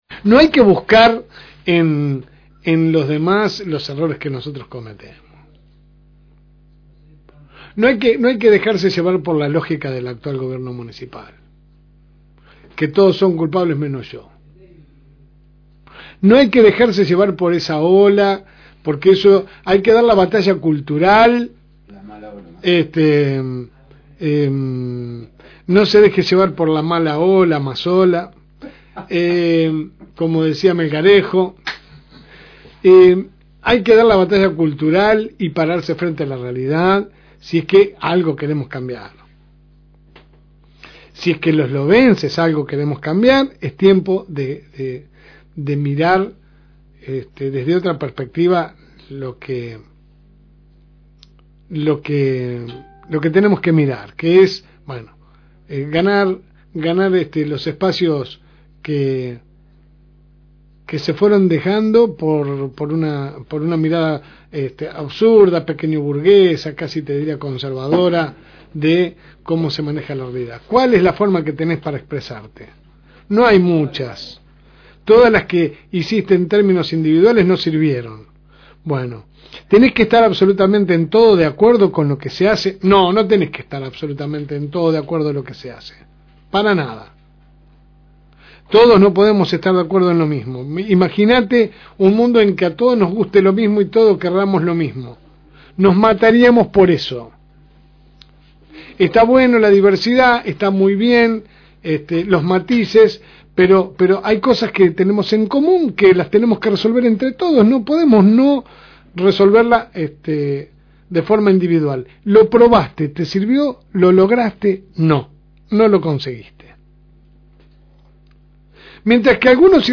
AUDIO – Editorial – FM Reencuentro